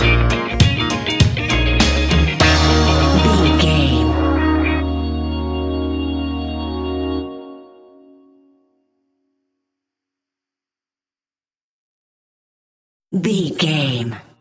Aeolian/Minor
B♭
laid back
chilled
off beat
drums
skank guitar
hammond organ
percussion
horns